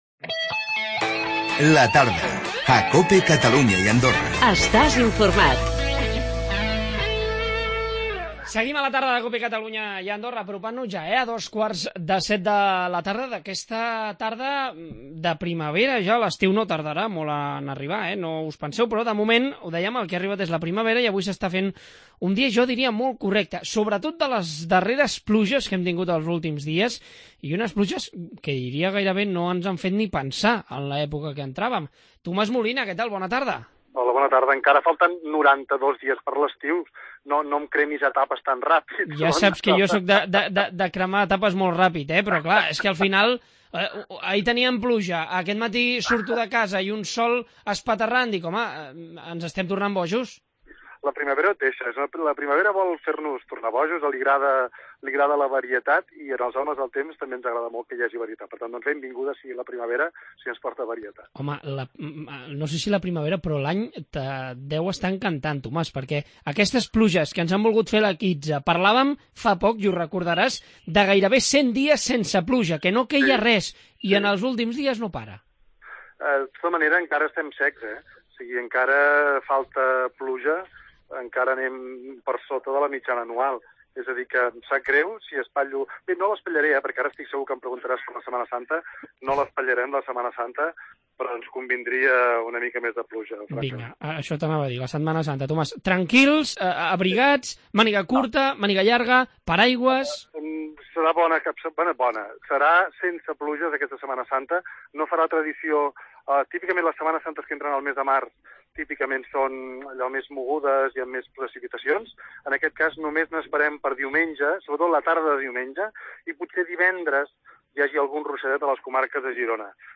Arriba la primavera, i amb ella... El bon temps? Les al·lèrgies? Hem parlat amb el meteoròleg Tomàs Molina